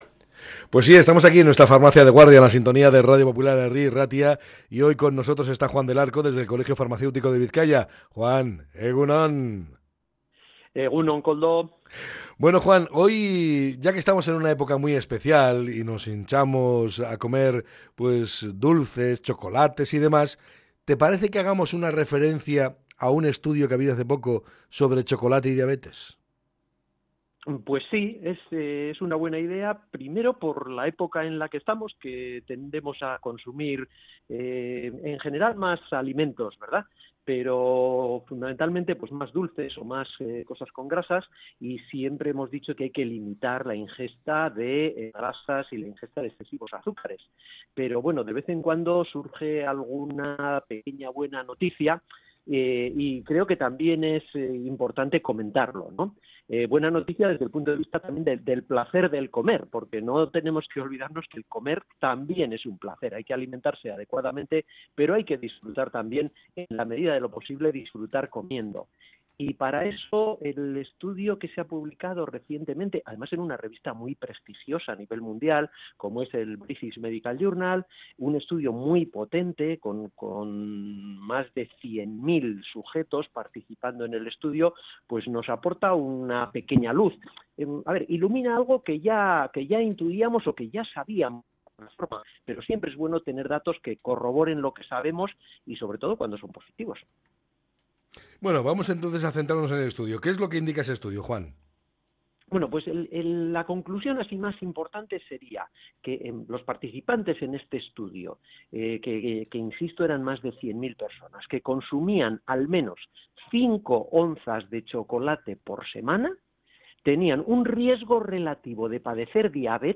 Este ha sido el tema principal en torno al que ha versado la charla